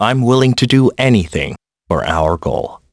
Riheet-vox-get.wav